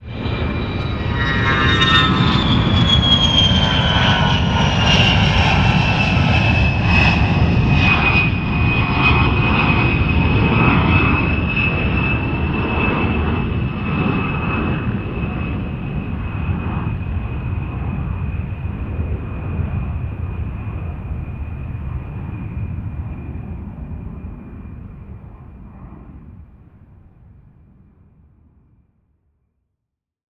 airplane-sound-effect